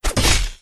Heavy_Sword2.wav